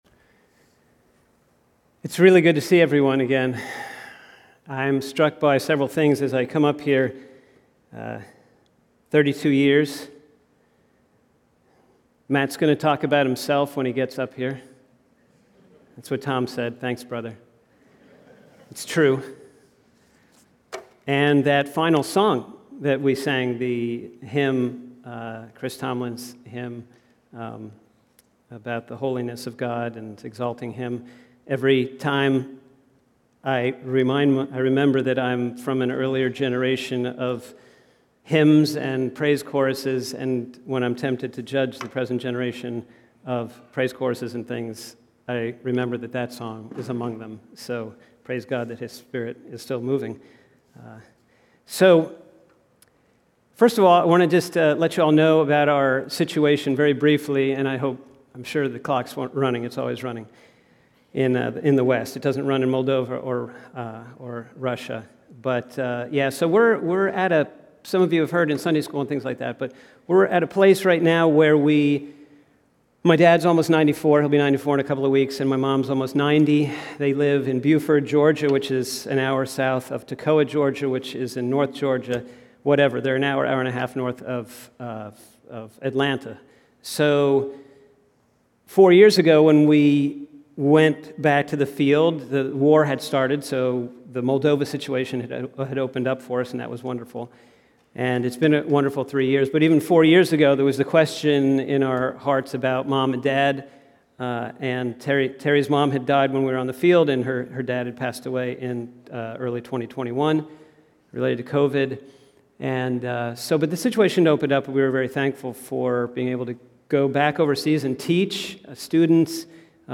audio_file Sermon Audio notes Transcript podcasts Podcast description Notes Share Description How does faith grow over a lifetime?